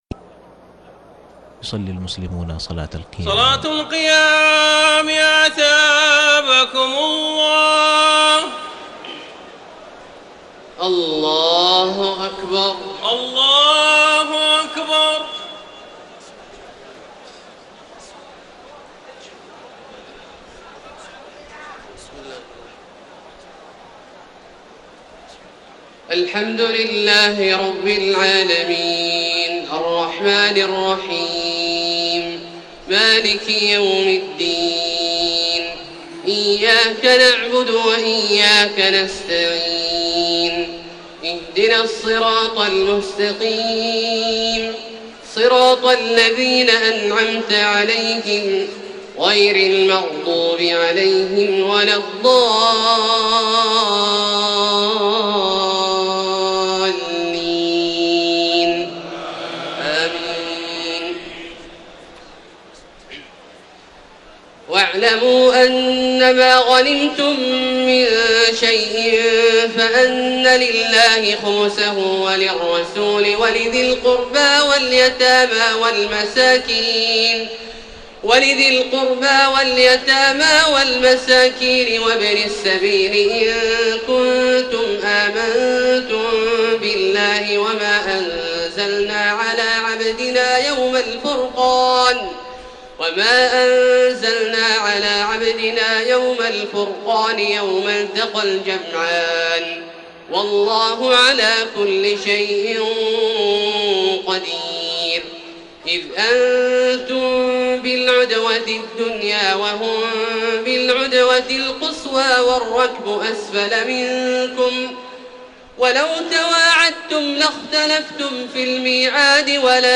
تراويح ليلة 30 رمضان 1433هـ من سورتي الأنفال (41-75) و التوبة (1-33) Taraweeh 30 st night Ramadan 1433H from Surah Al-Anfal and At-Tawba > تراويح الحرم المكي عام 1433 🕋 > التراويح - تلاوات الحرمين